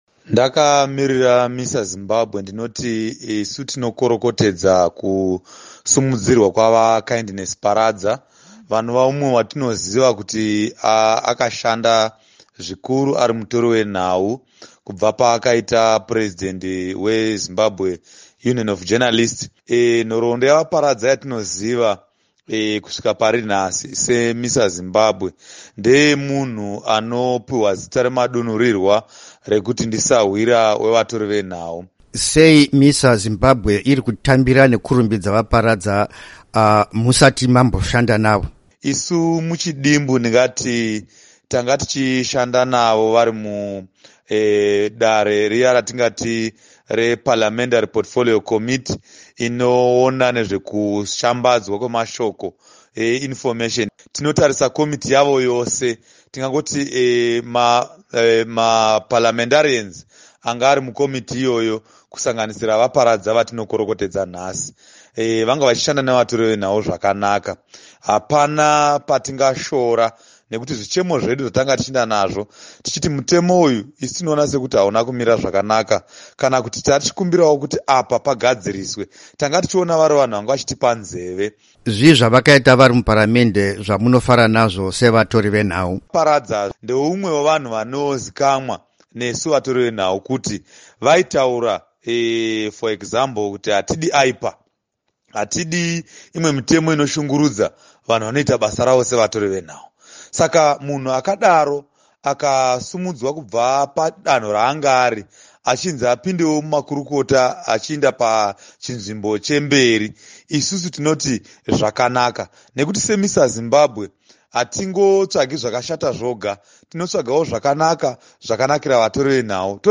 Hurukuro
vari parunhare ku Harare ne Studio 7.